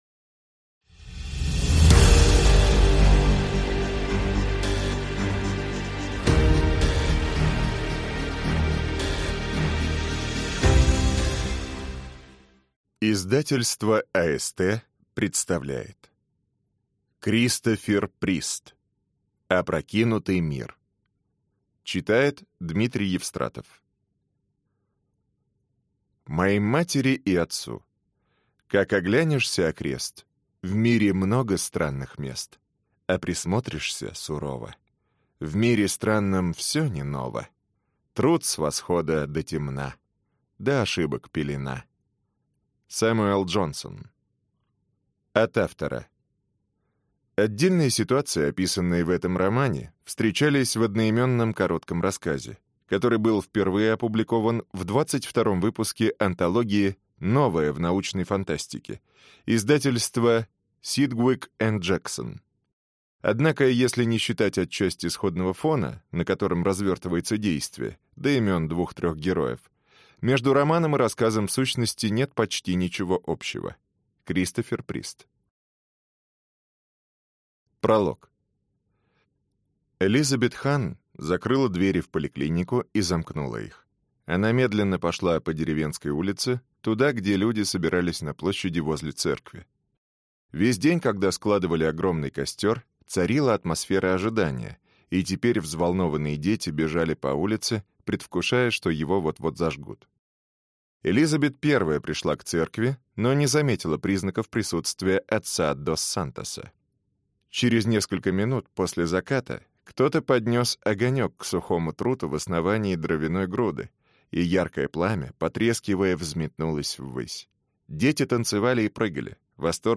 Аудиокнига Опрокинутый мир | Библиотека аудиокниг